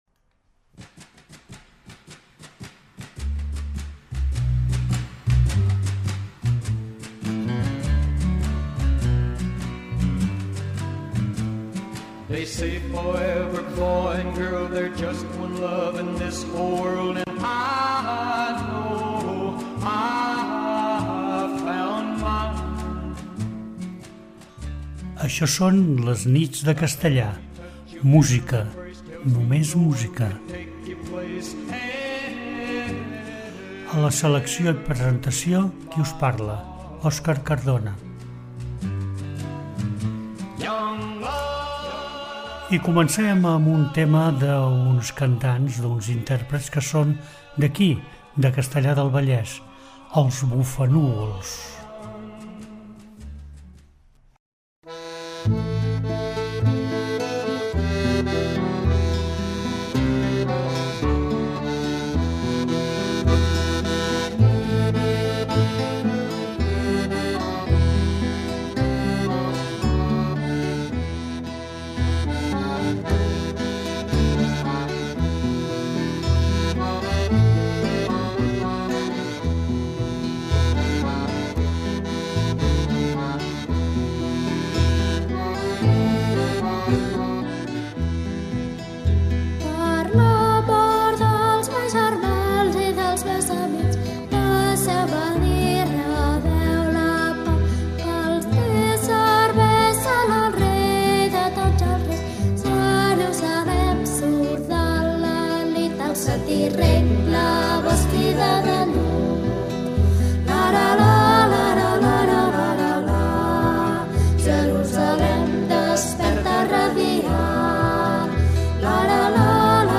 Les Nits de Castellar és un espai que pretén apropar l'oient a la música de diferents èpoques i autors, en un to intimista i relaxant.